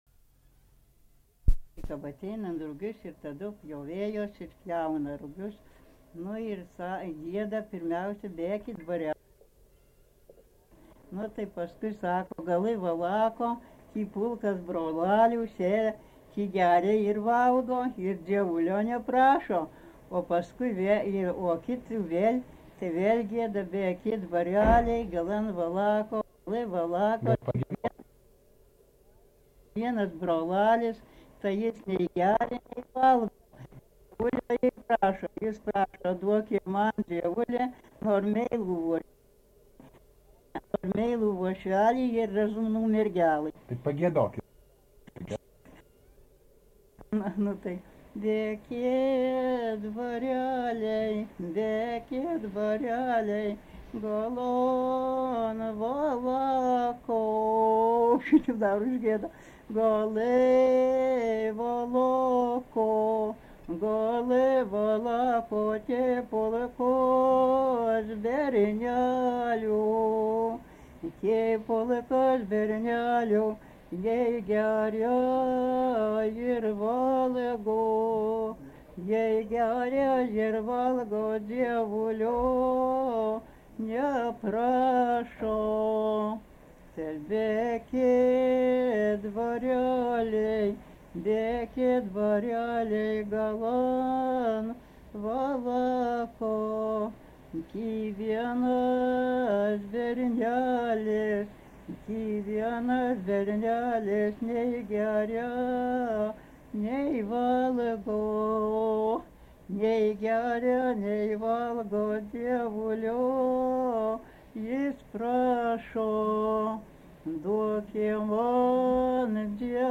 Dalykas, tema daina
Erdvinė aprėptis Galintėnai
Atlikimo pubūdis vokalinis